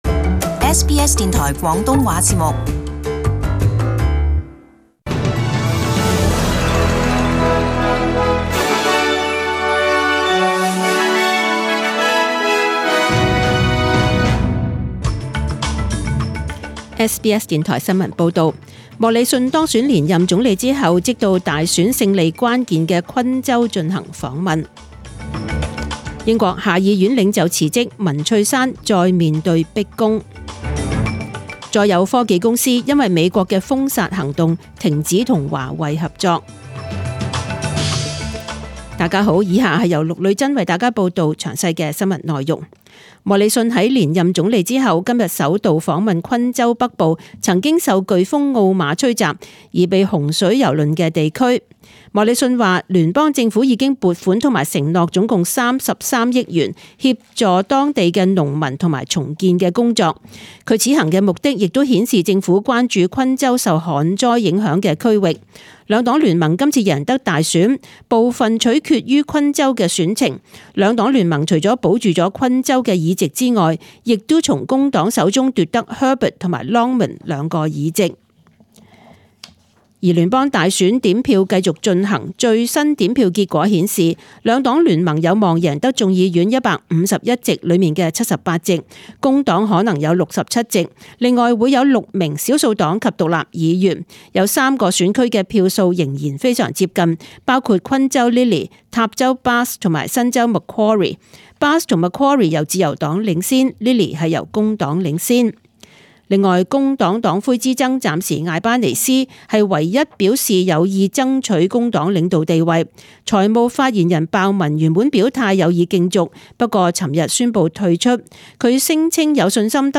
請收聽本台為大家準備的詳盡早晨新聞